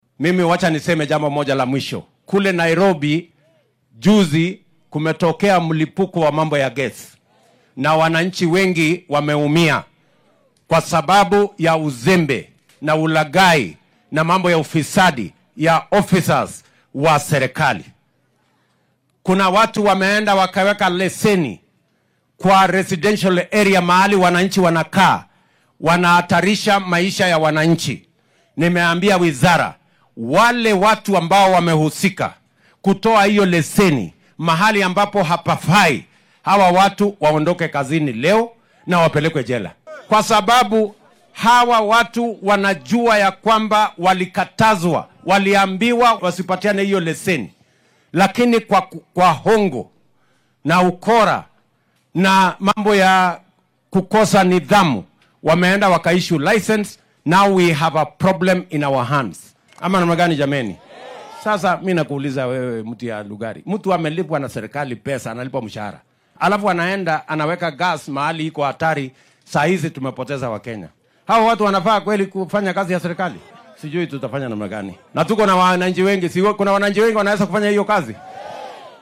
Madaxweynaha ayaa arrintan ka hadlay xilli uu deegaanka Lugari ee ismaamulka Kakamega ee Galbeedka dalka ka dhagax dhigayay dhismaha suuqa Chekalini.
William-Ruto-1-1.mp3